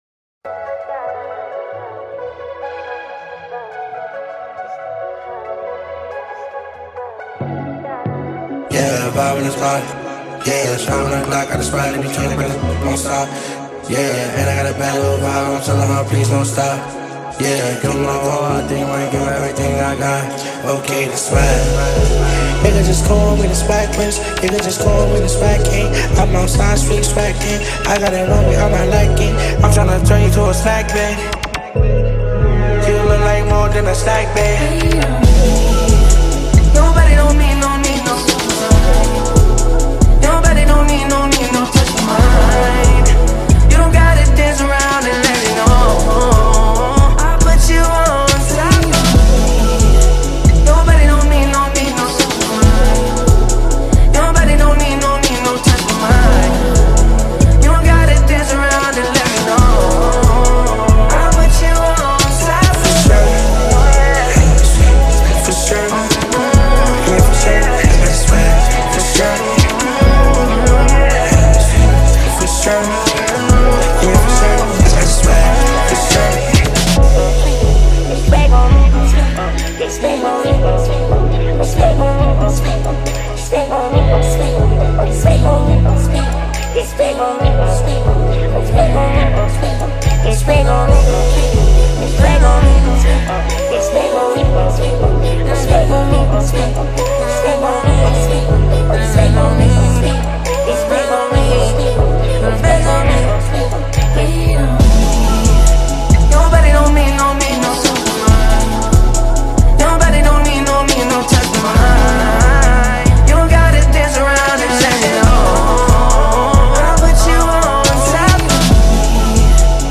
Hip Hop
American singer-songsmith